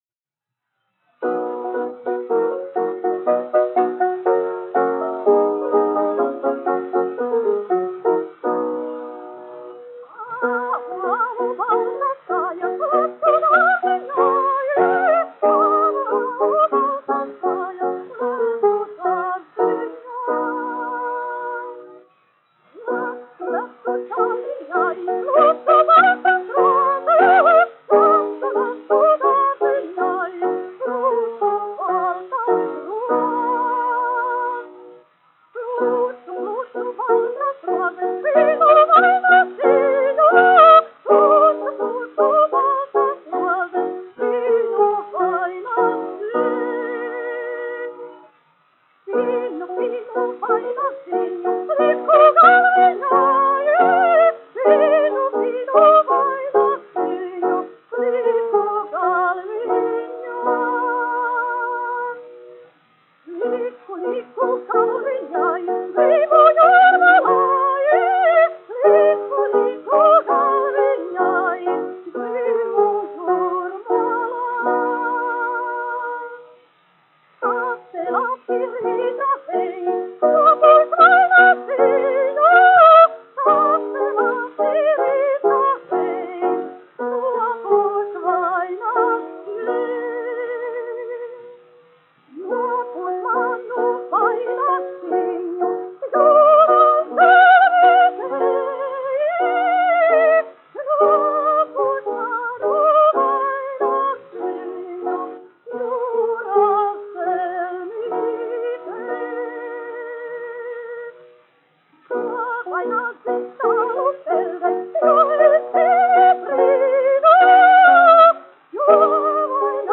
Āvu, āvu baltas kājas : ar klavieru pavadījumu
1 skpl. : analogs, 78 apgr/min, mono ; 25 cm
Latviešu tautasdziesmas
Skaņuplate